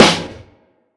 Live_Snro (1).wav